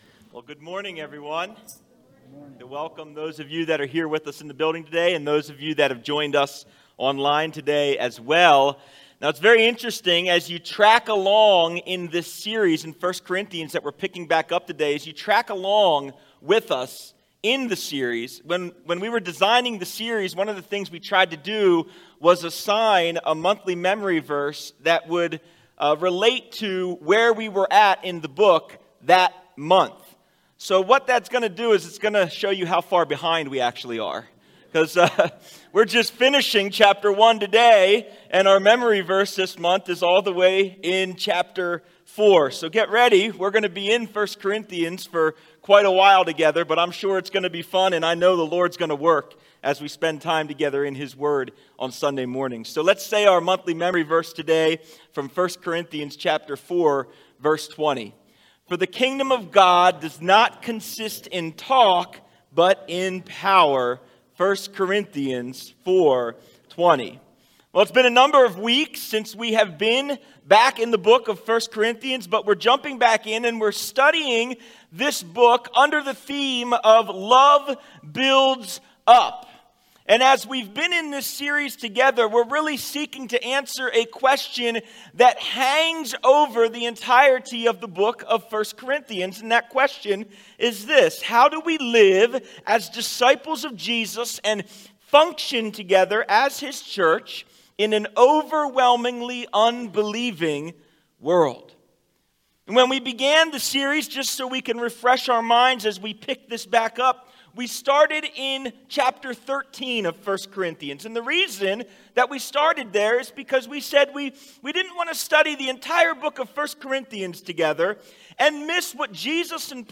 Sermons | Calvary Monument Bible Church